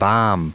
Prononciation du mot balm en anglais (fichier audio)
Prononciation du mot : balm